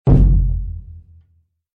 surdo-7.mp3